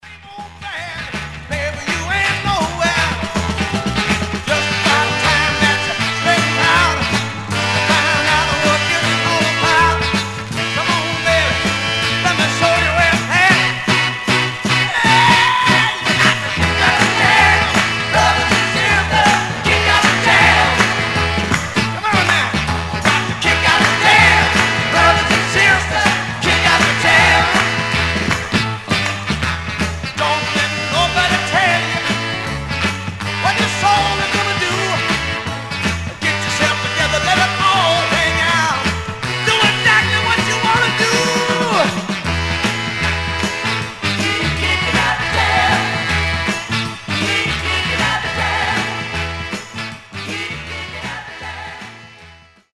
Stereo/mono Mono
Folk